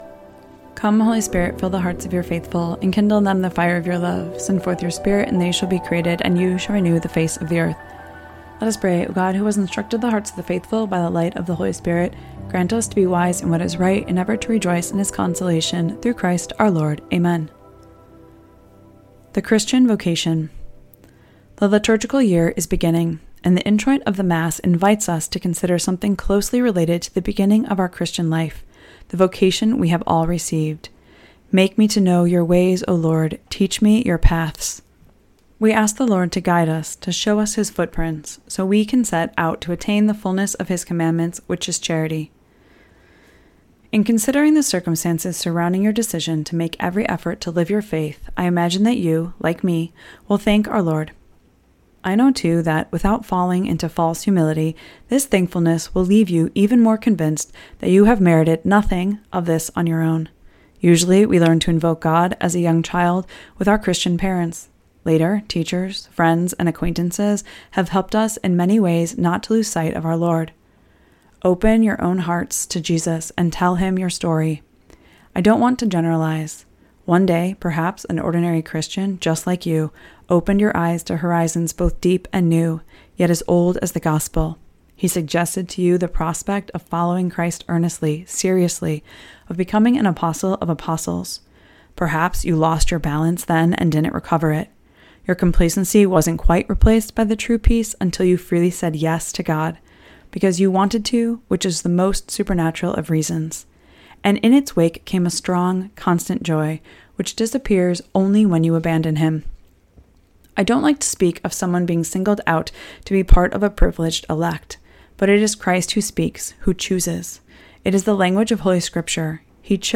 Spiritual Reading with Scepter Publishers